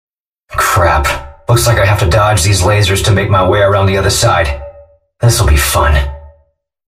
HELP! Подскажите, как сделать такой же звонкий (резонирующий) реверб с металлическим призвуком? Может есть VST-плагины дающий такой звонящий хвост?